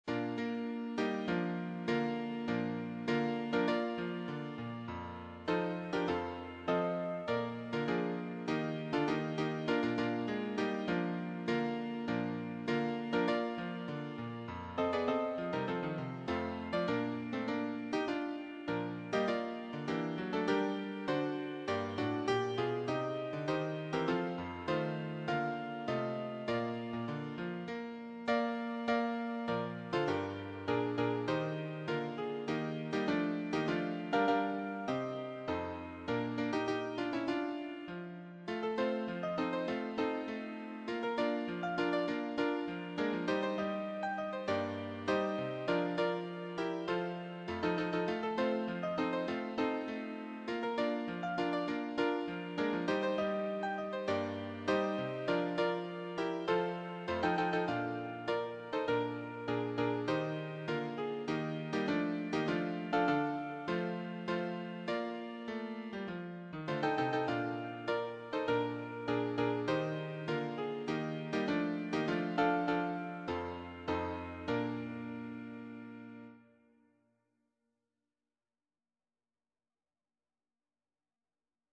choir SA(A)T(T)B